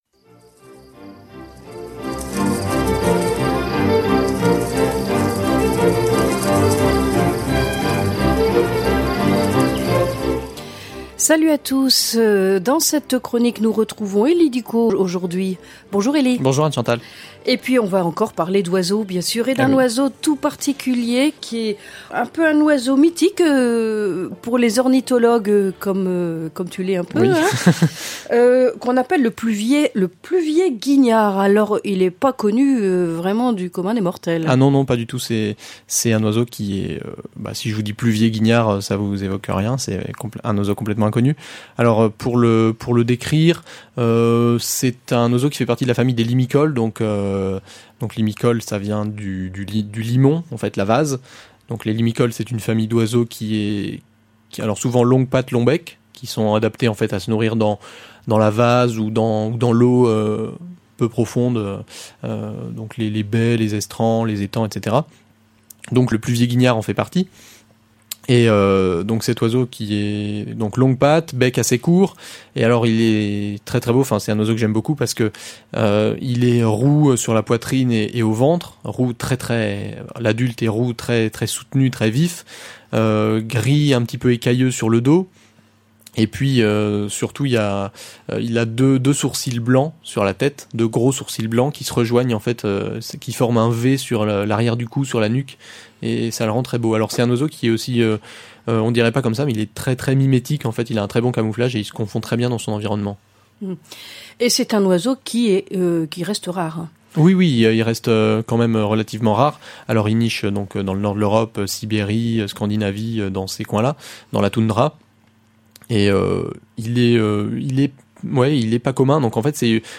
Chronique nature C'est un bel oiseau aux longues pattes et au long bec qui aime à se nourrir du côté des étangs.